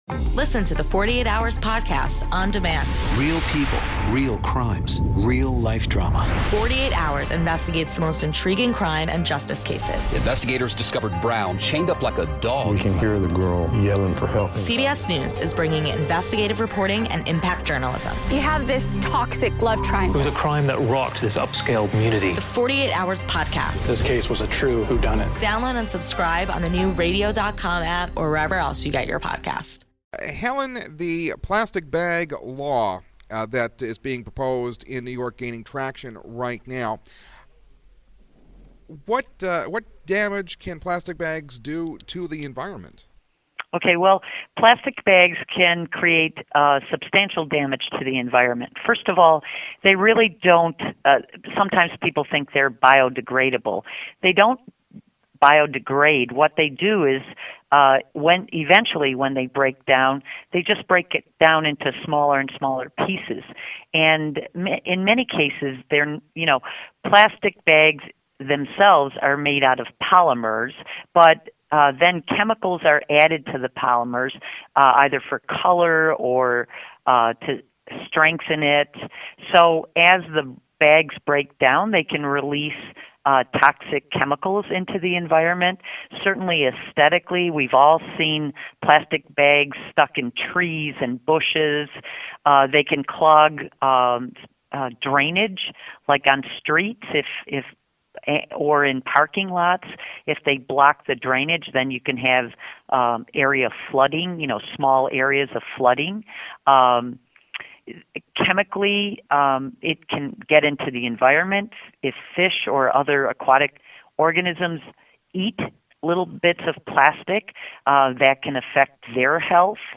WBEN 930 AM Radio
Interview